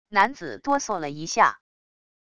男子哆嗦了一下wav音频